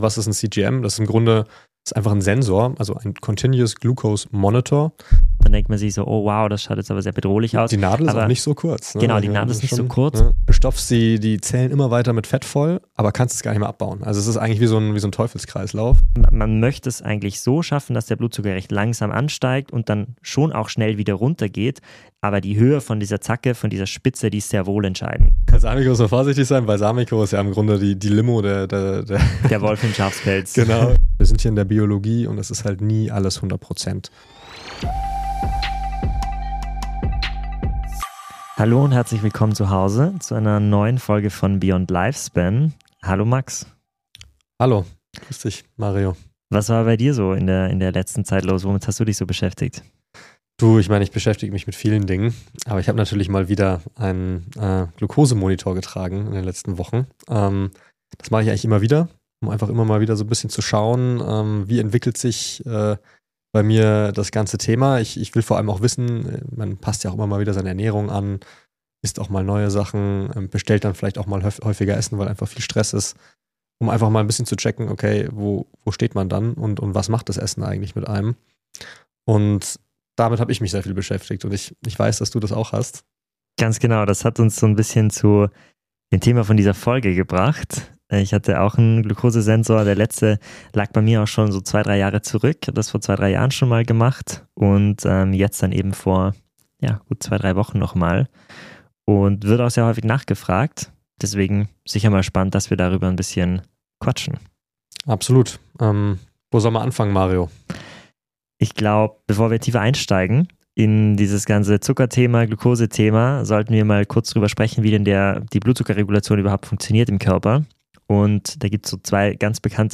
Expertengespräche: Wir führen spannende Interviews mit führenden Köpfen aus den Bereichen Präventivmedizin, Biohacking und Nahrungsergänzungsmittel, um dir Einblicke und Perspektiven zu bieten, die du sonst nirgendwo findest.